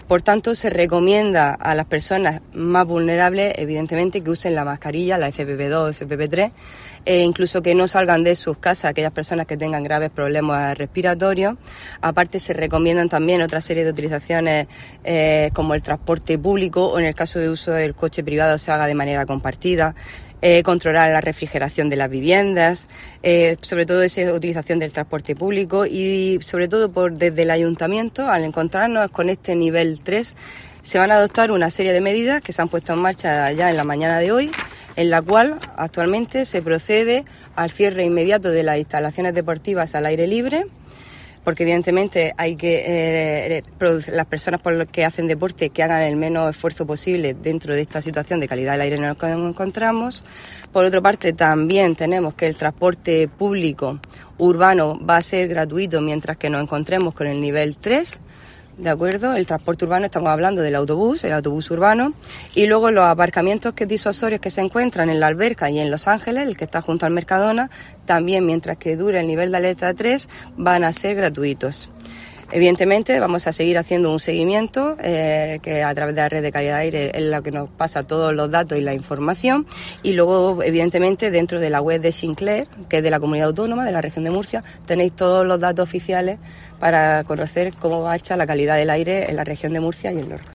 María Hernández, concejal del PP de Lorca